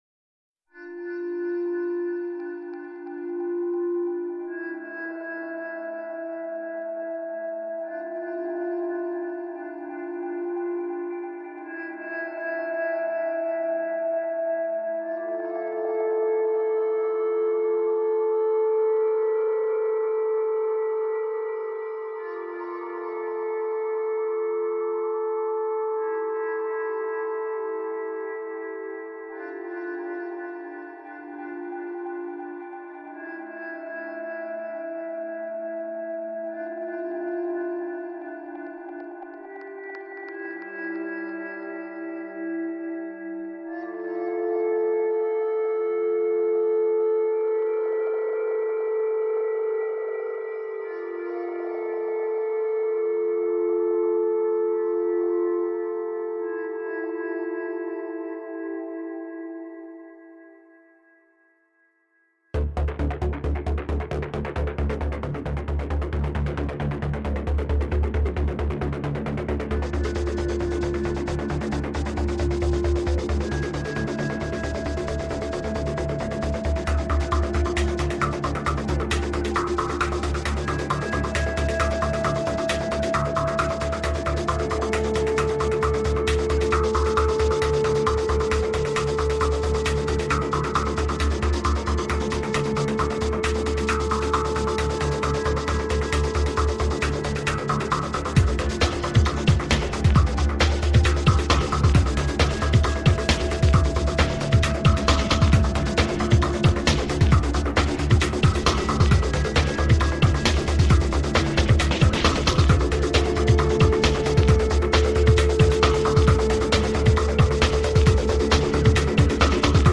Soundtrack, Classical, Electronic